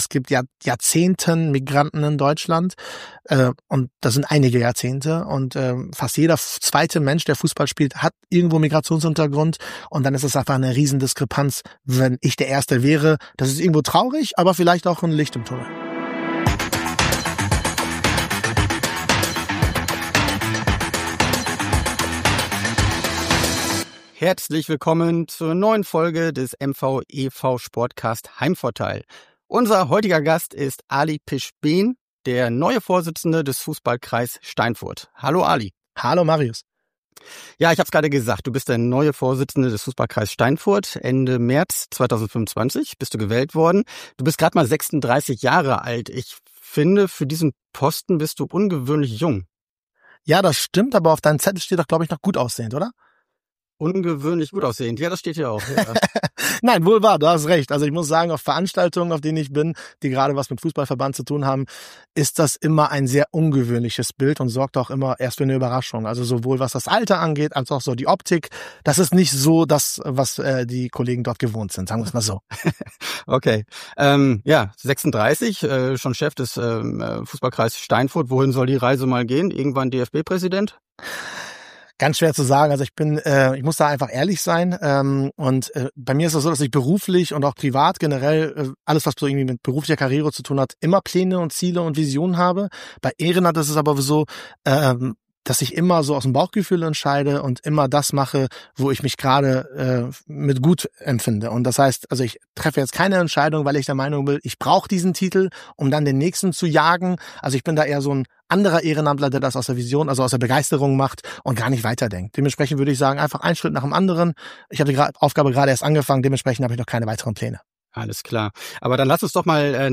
Ein Gespräch mit einem in vielerlei Hinsicht ungewöhnlichen Sportfunktionär.